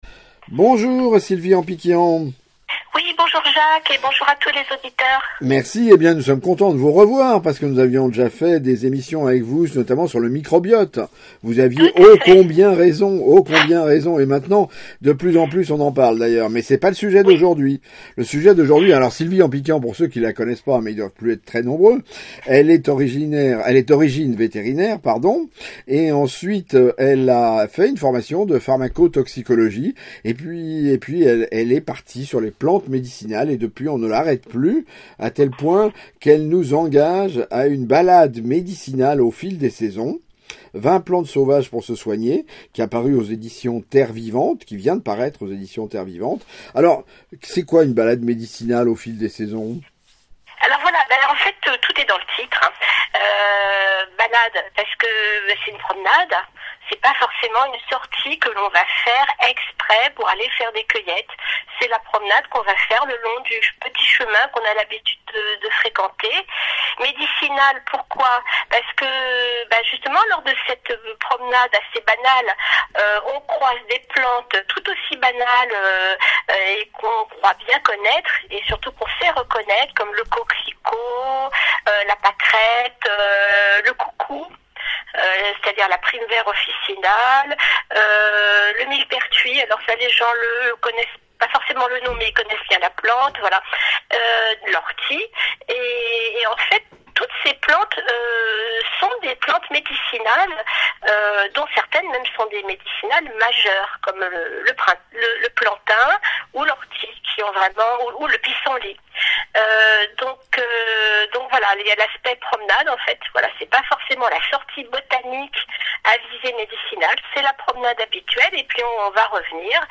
Les plantes médicinales pour en savoir plus: suivez l’interview d’une experte en la matière